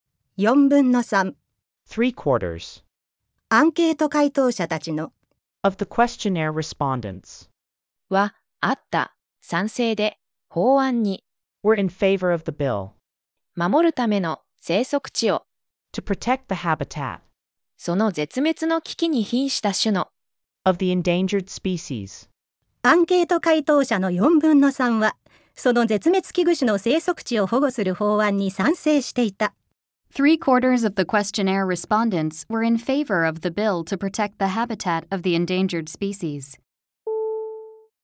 (A) 読みし音声